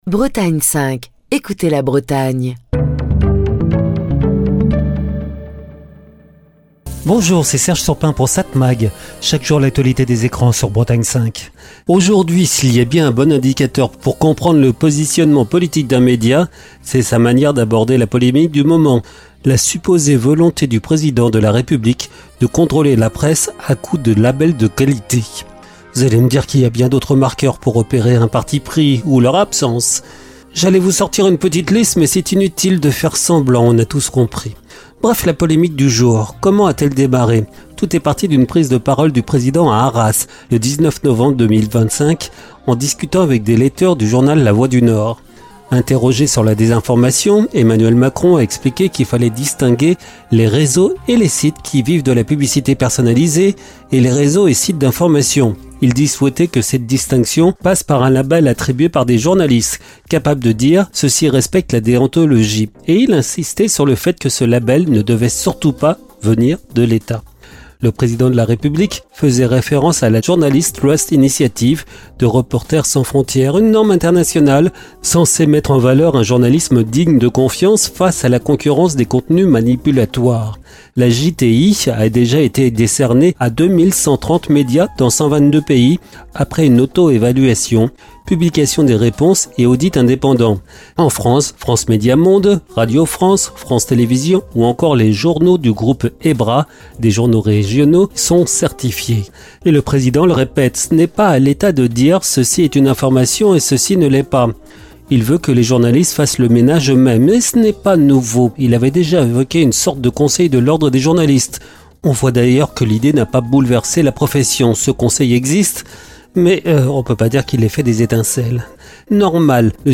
Chronique du 4 décembre 2025.